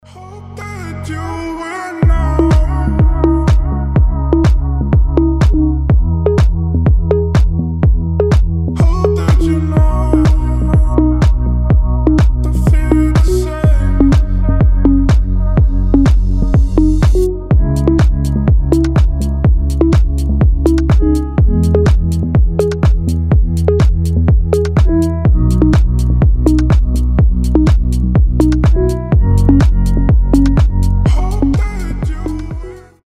• Качество: 320, Stereo
deep house
атмосферные
расслабляющие